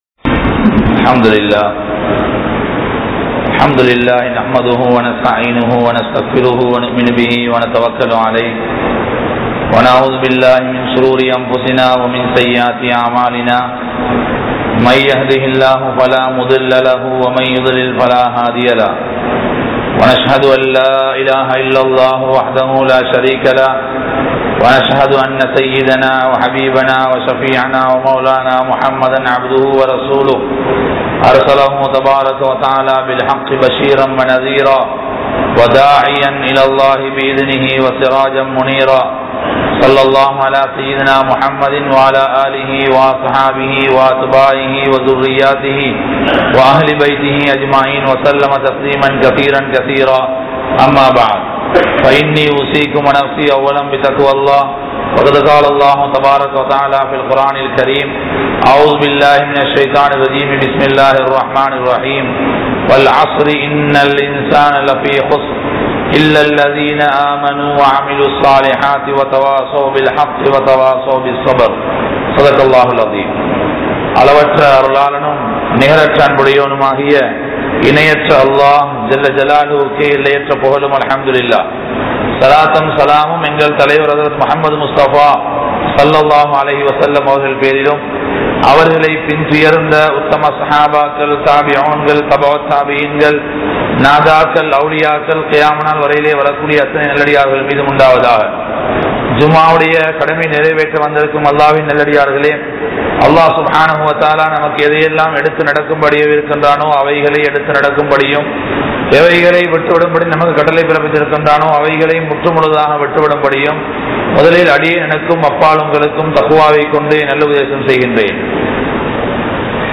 Ulahaththai Aalum 03 Paavangal (உலகத்தை ஆளும் 03 பாவங்கள்) | Audio Bayans | All Ceylon Muslim Youth Community | Addalaichenai
Dehiwela, Junction Jumua Masjith